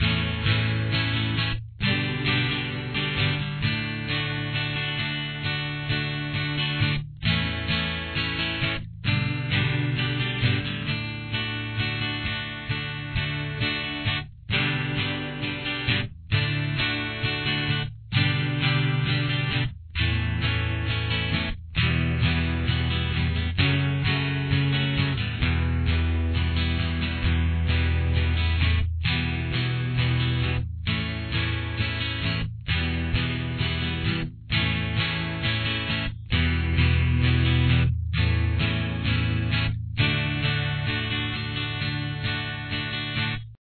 • Key Of: D
• Instruments: Acoustic Guitar